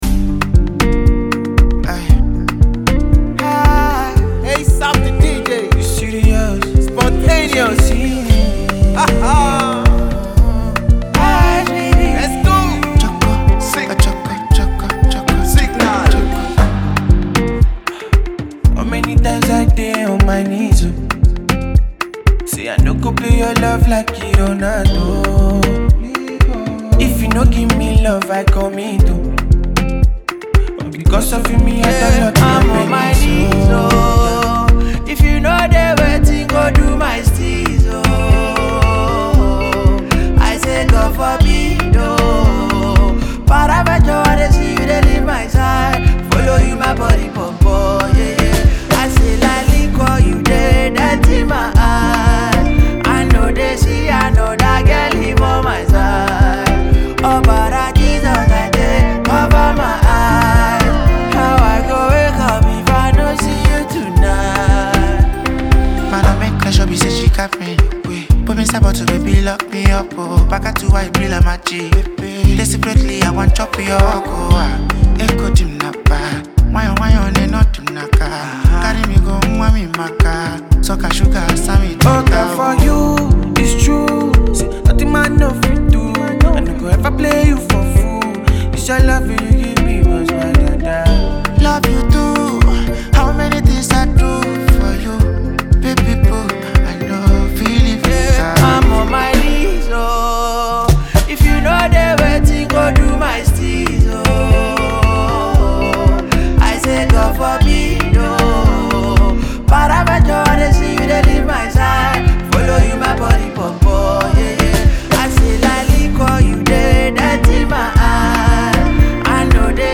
fresh romantic Afropop single
Smooth Sound and Simple Love Message
The chorus is catchy without being too loud or dramatic.